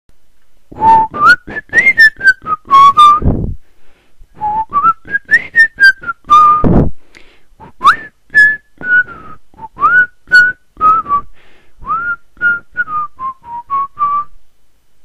Hoffe man kann man gepfeife erkennen;) LG Beitrag melden Bearbeiten Thread verschieben Thread sperren Anmeldepflicht aktivieren Anpinnen Thread löschen Thread mit anderem zusammenführen Markierten Text zitieren Antwort Antwort mit Zitat